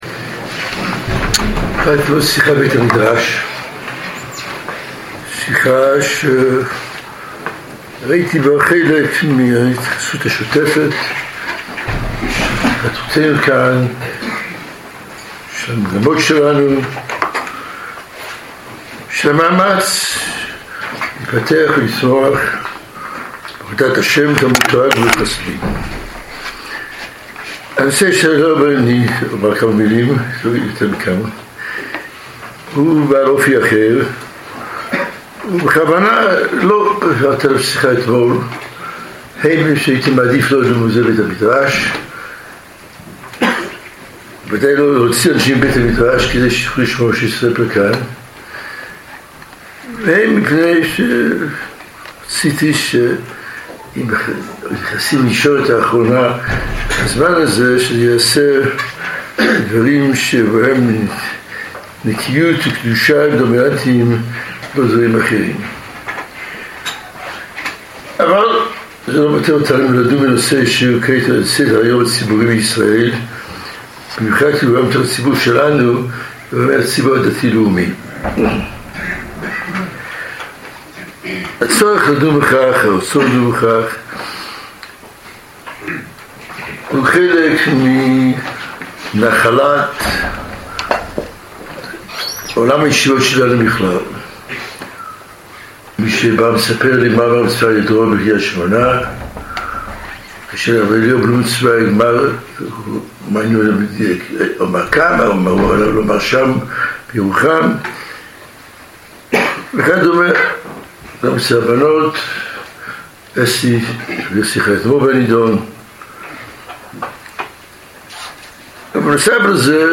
הרב ליכטנשטיין מתייחס לפרשת הרב אלון: "טרגדיה נוראה"
0:00 0:00 הורדה 100 1.0x 0.25x 0.5x 0.75x 1.0x 1.25x 1.5x 1.75x 2.0x הרב ליכטנשטיין מתייחס לפרשת הרב אלון: "טרגדיה נוראה" בשיחה בישיבה גולל הרב אהרן ליכטנשטיין את השתלשלותה המלאה של פרשיית הרב אלון.